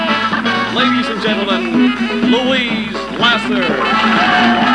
In addition, I am pleased to be able to include several files recorded from the rarely seen episode of "Saturday Night Live" which Louise Lasser hosted on July 24, 1976.
louisesnl02 - Don Pardo introduces Louise's stage entrance.  (102kb)